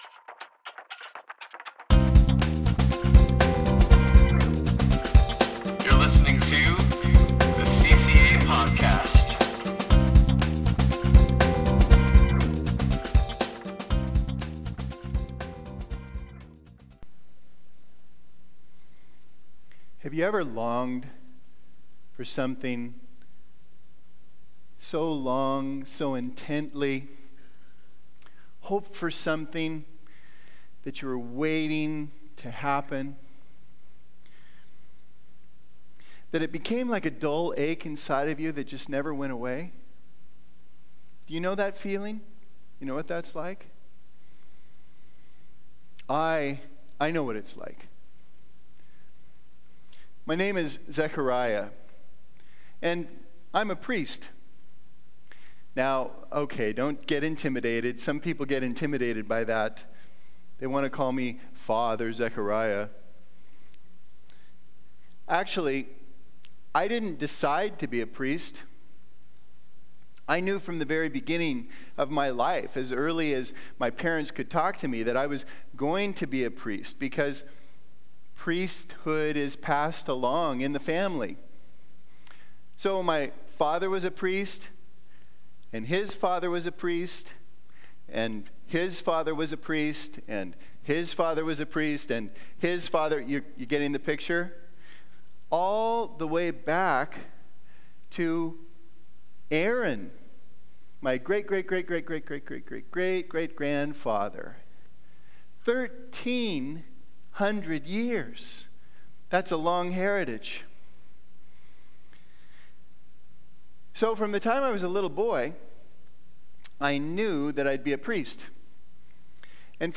Message - Calvary Christian Assembly